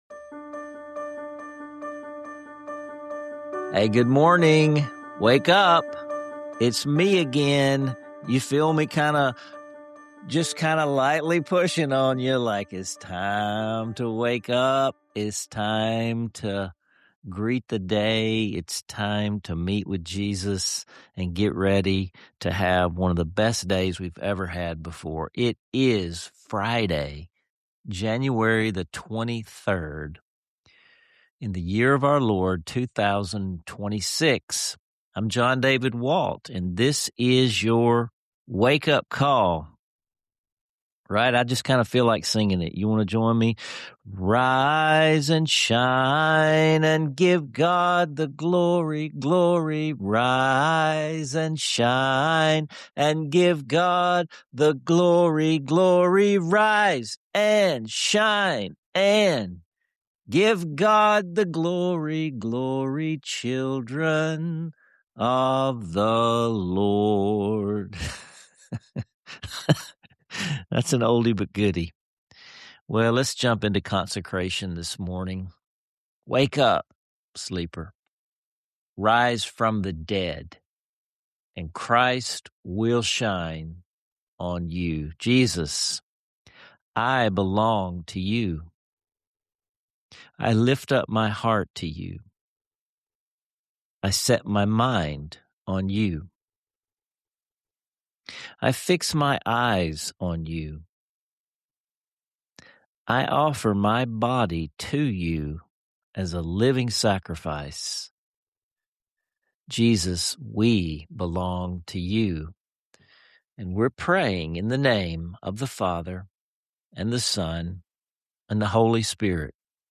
for an inspiring conversation that redefines how we approach wisdom and spiritual formation.